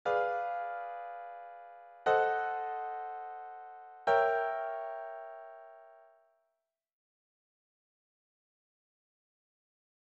基本的に響きが異なる減七の和音は次の三種類だけで、ほかは同名異音で表現されているだけです。